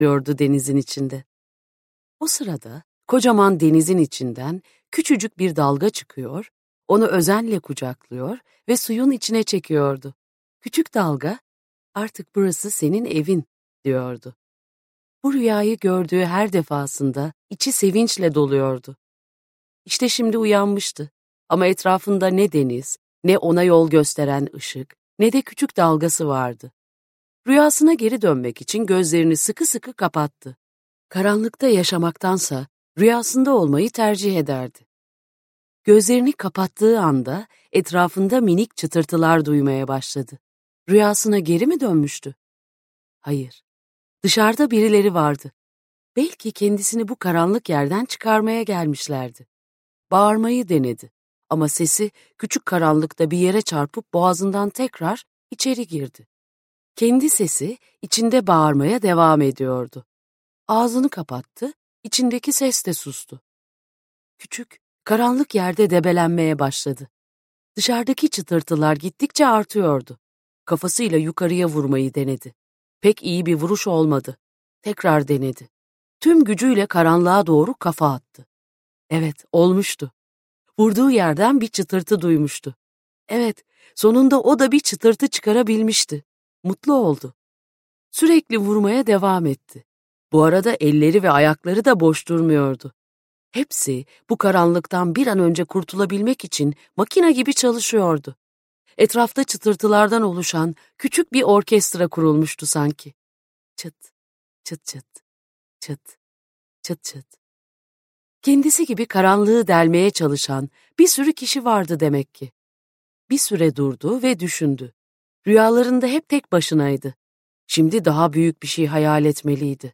Dansçı Caretta - Seslenen Kitap